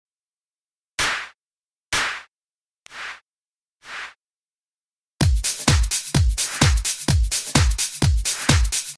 Tip 1: Adding a ‘Clap Build’
Once it sounds right manually move it into position before your main clap sounds to get that build up effect: